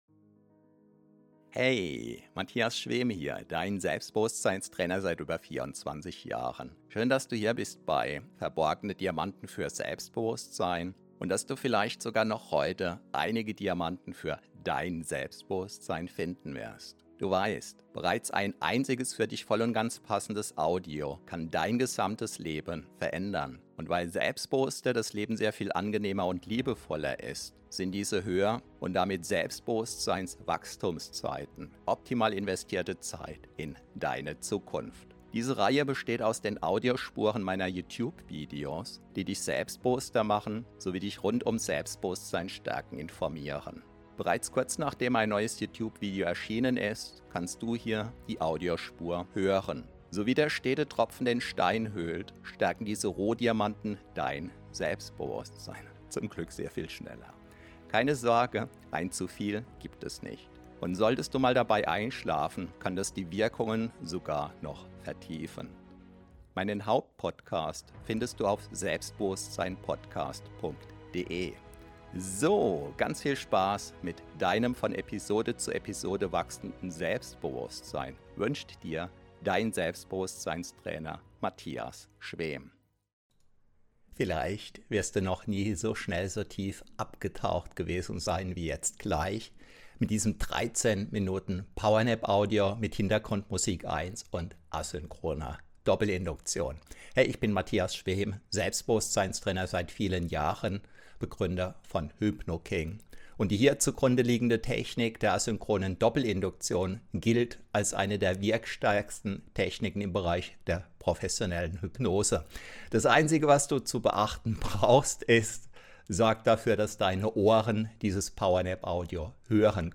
Die Asynchrone Doppelinduktion ist kein gewöhnlicher Powernap, sondern das Ergebnis aus 28 Jahren Hypnose- und Selbstbewusstseinstraining.
Achtung: Für maximale Wirkung verwende unbedingt hochwertige Kopfhörer!